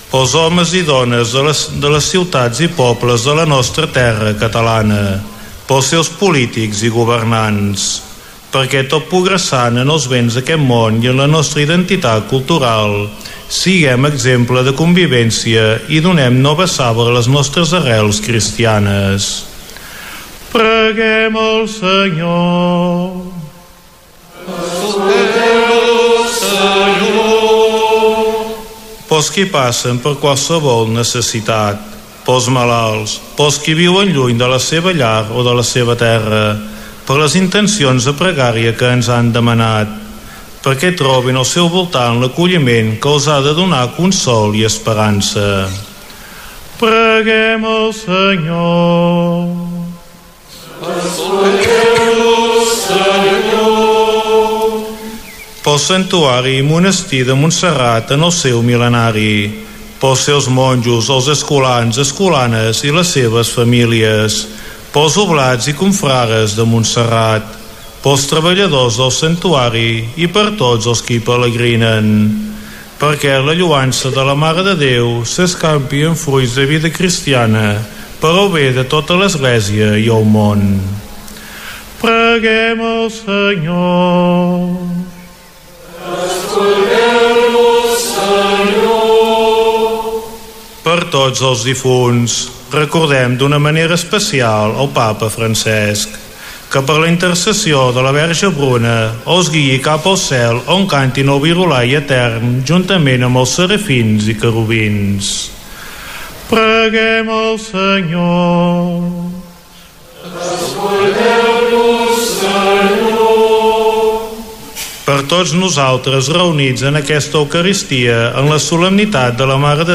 5f9438ed65690ff9ed3d221bcd2589c5c38388a1.mp3 Títol Ràdio Estel Emissora Ràdio Estel Cadena Ràdio Estel Titularitat Privada nacional Nom programa Missa conventual de Montserrat Descripció Retransmissió des de l'exterior de la Basílica de Santa Maria de la missa conventual el dia que la verge de Montserrat ha sortit en processó fins a la plaça de Santa Maria per celebrar el mil·lenari de la fundació del monestir.